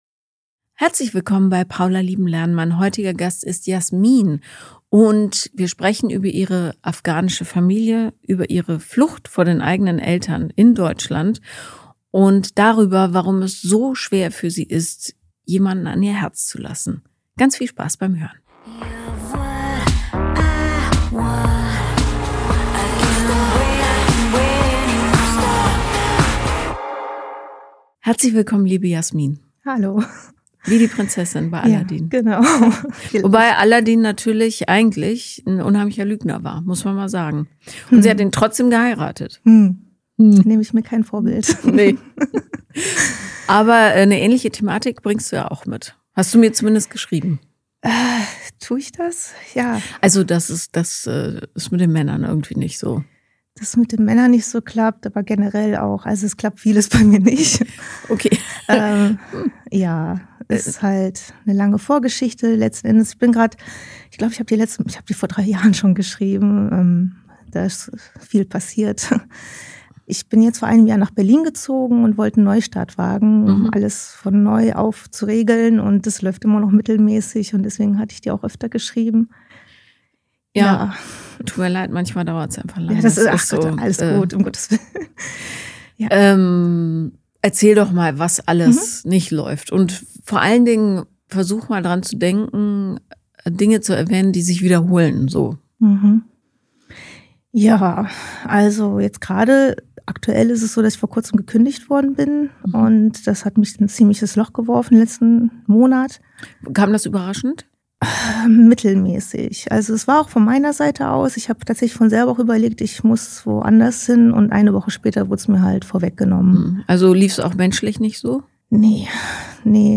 Since her Thought SNOB method is designed to help people heal through various modalities, a workshop-style lecture about the vagus nerve is fitting.